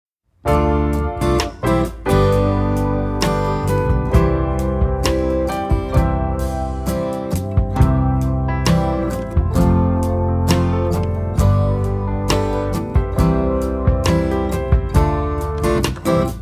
Listen to a sample of this instrumental song.